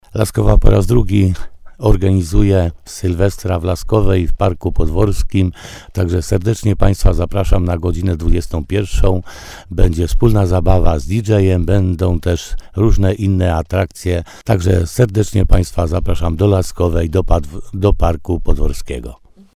W programie jest między innymi zabawa taneczna, ale też ognisko, czy poczęstunek ciepłym bigosem – zapowiada wójt gminy Laskowa, Piotr Stach.